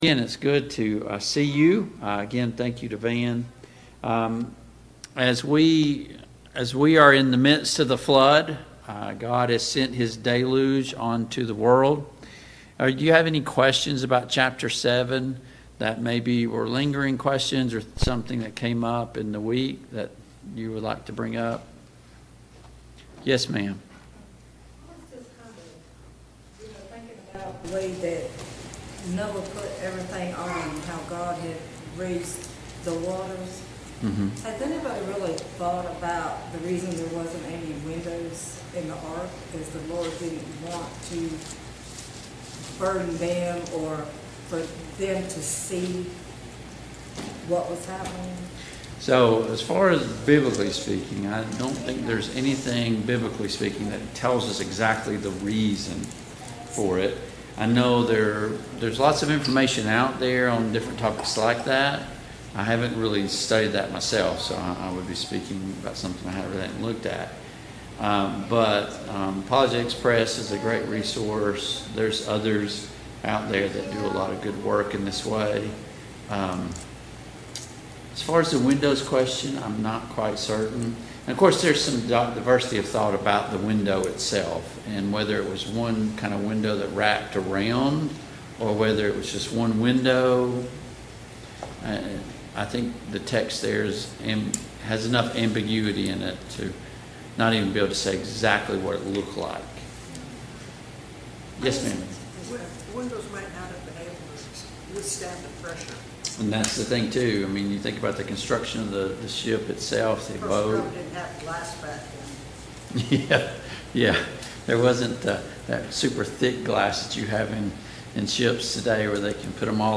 Genesis 8 Service Type: Family Bible Hour Topics: Noah and the Flood , The Flood « Before the Throne of God 21.